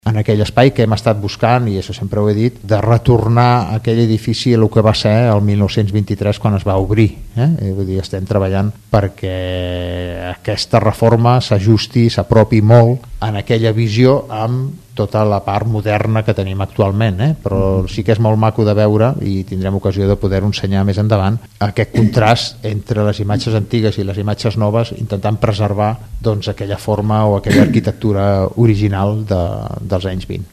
L’alcalde, Carles Motas, és optimista i compta amb el suport expressat pel president Salvador Illa.